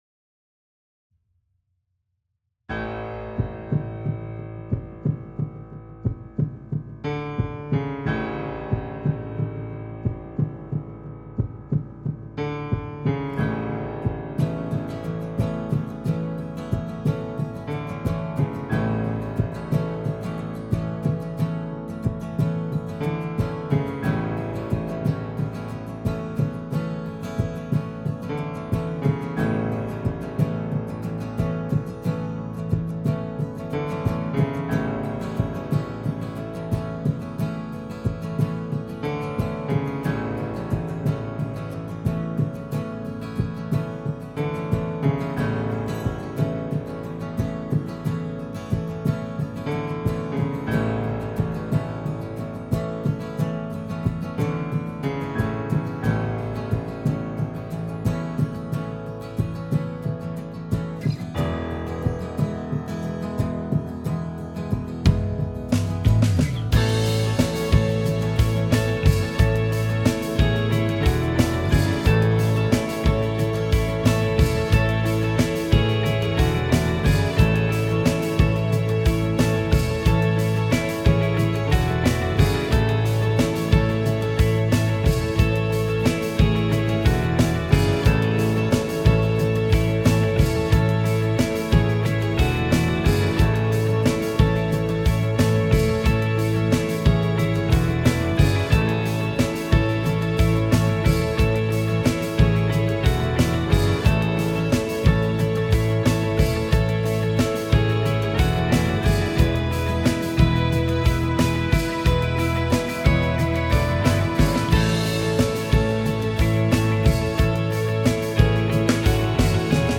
BPM : 90
Tuning : E
Without vocals